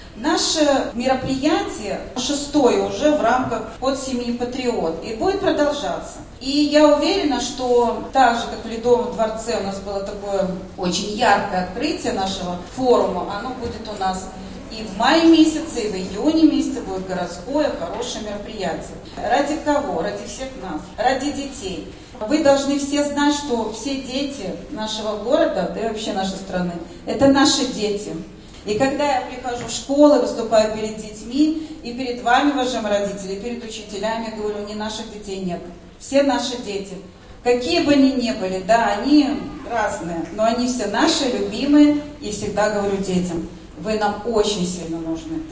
В Барановичах масштабно прошел семейный форум «Крепкая семья — процветающая страна!».
Между гостями и аудиторией состоялся доверительный живой разговор о самом сокровенном: что делает семью крепостью, а страну – единой и процветающей.